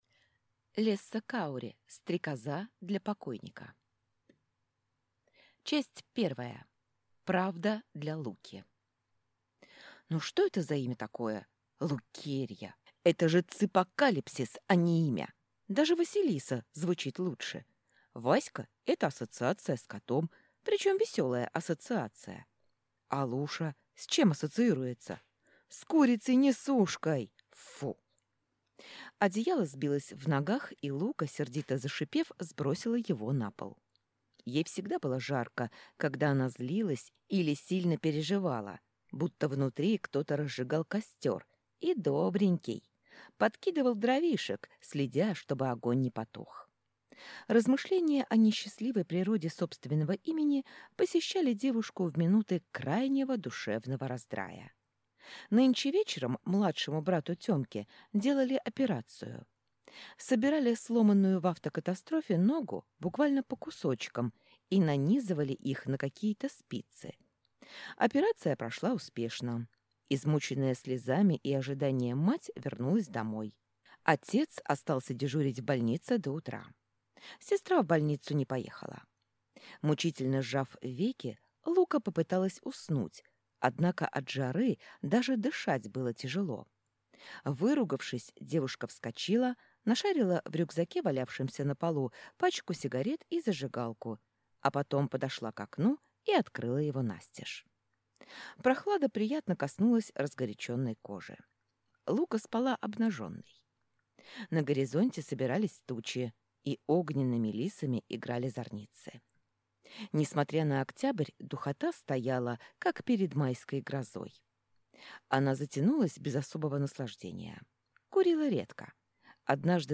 Аудиокнига Стрекоза для покойника | Библиотека аудиокниг